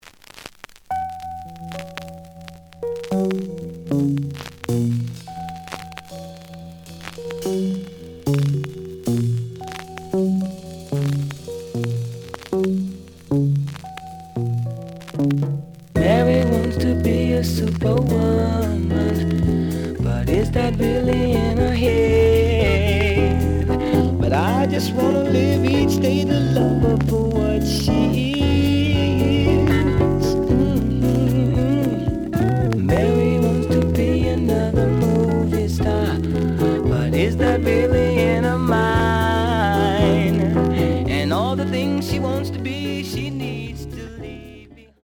The audio sample is recorded from the actual item.
●Genre: Soul, 70's Soul
Some noise on A side.